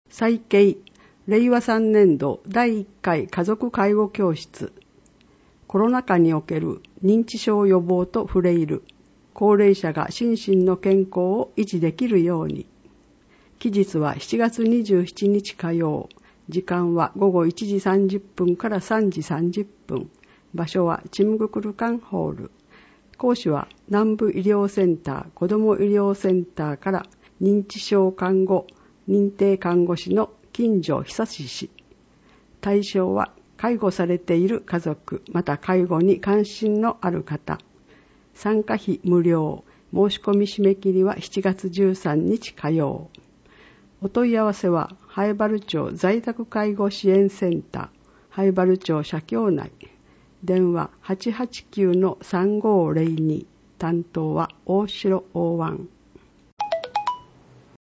以下は音訳ファイルです